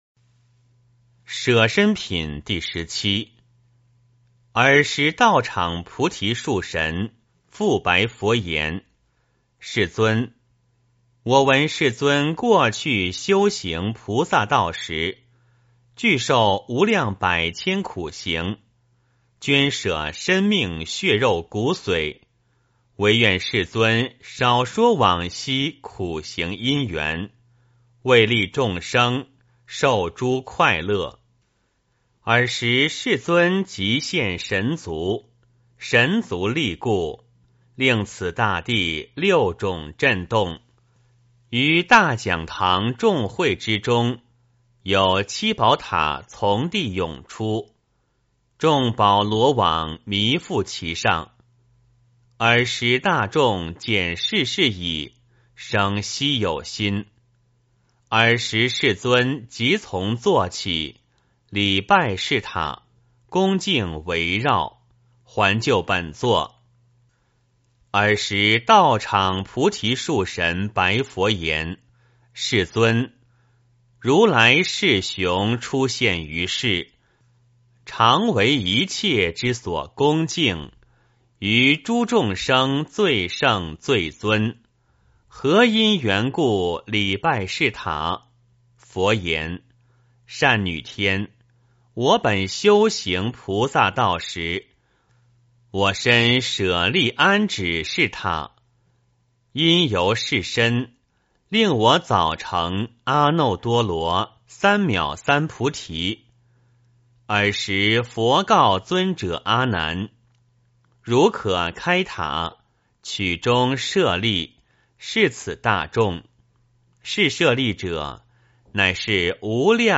金光明经-舍身品第十七 诵经 金光明经-舍身品第十七--未知 点我： 标签: 佛音 诵经 佛教音乐 返回列表 上一篇： 金光明经-善集品第十二 下一篇： 金光明经-赞佛品第十八 相关文章 风入松--北京菩提心月佛教音乐团 风入松--北京菩提心月佛教音乐团...